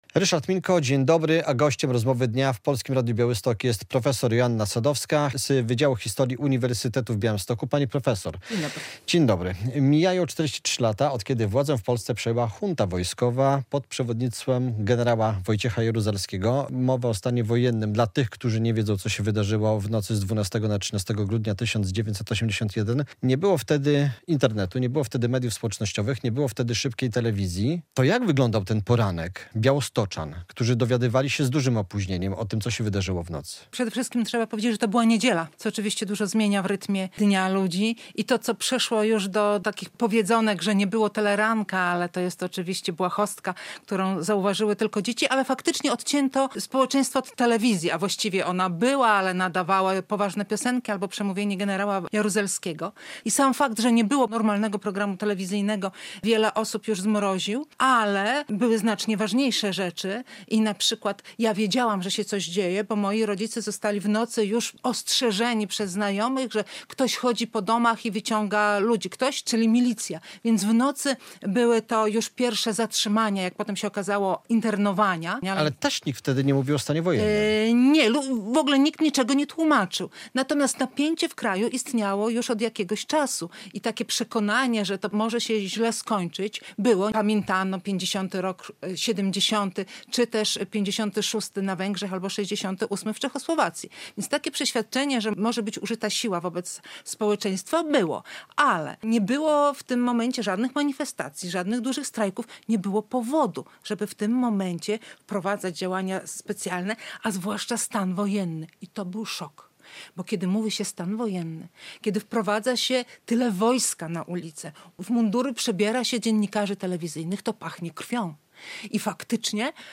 GOŚĆ RADIA BIAŁYSTOK